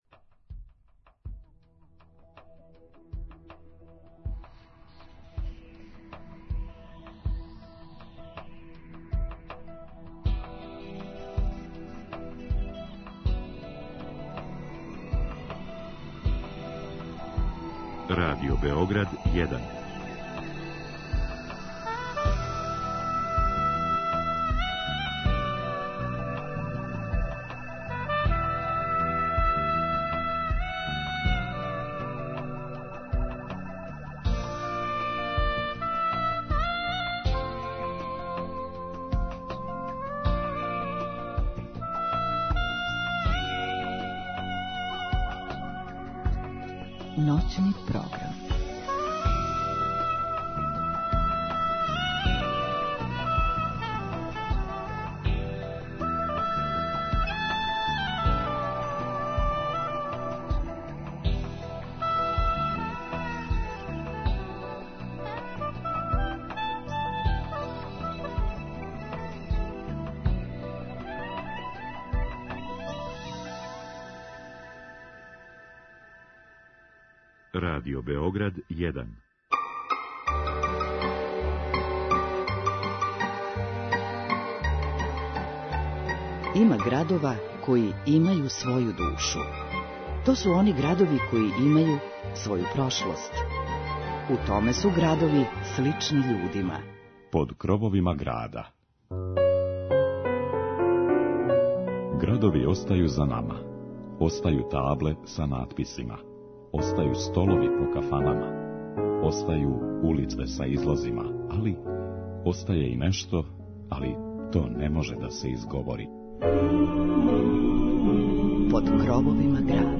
Многа позната имена наше традиционалне музике прославили су овај град који се налази у срцу Шумадије. Ноћас ћемо причом и музиком бити у Крагујевцу.